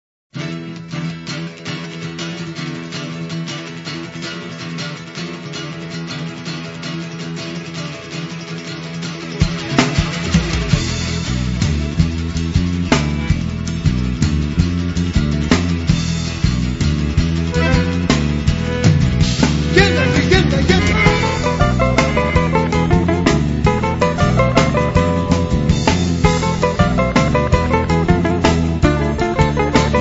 variazione su tema tradizionale macedone
• registrazione sonora di musica